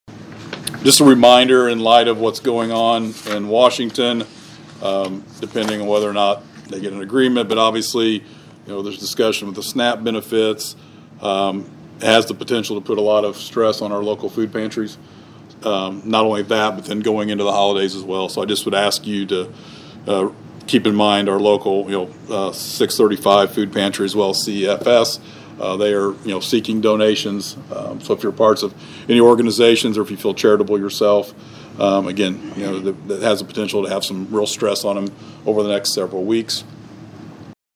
During Monday’s Vandalia City Council meeting, Mayor Doug Knebel just reminded everyone to help out the area’s food pantries during a time when they face more pressure than normal.